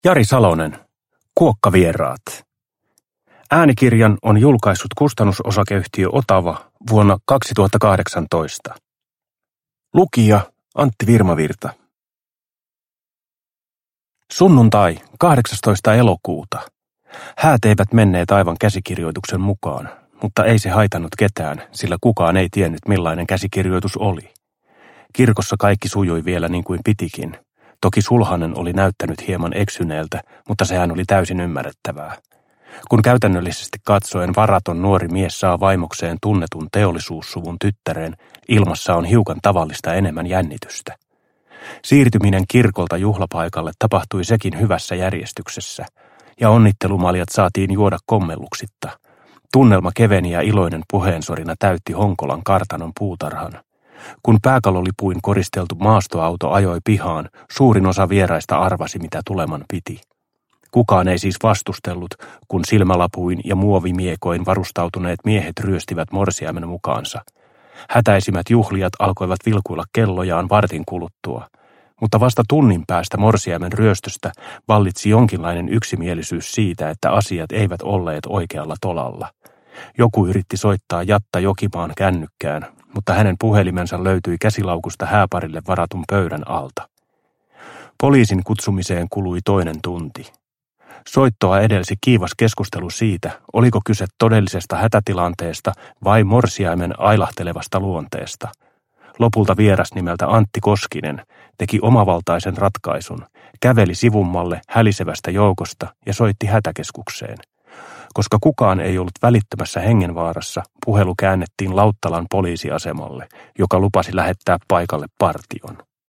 Kuokkavieraat – Ljudbok – Laddas ner